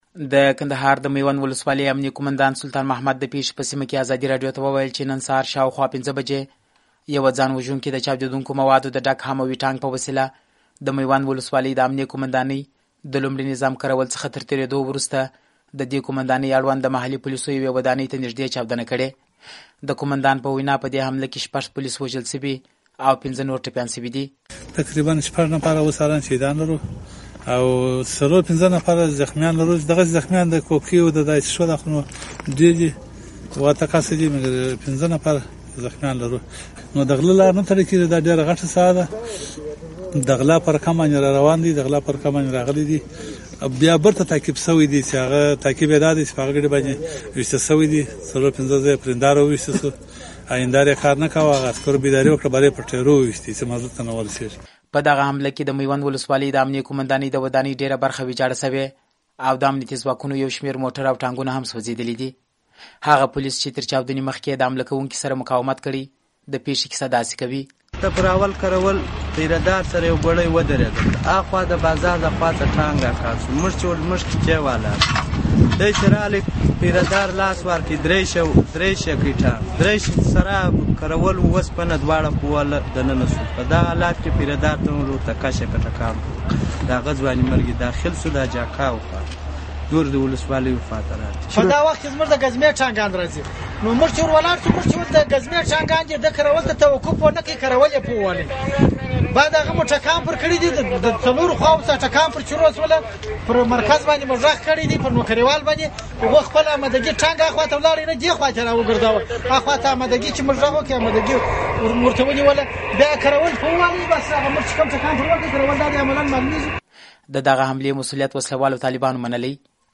د رښتیني راپور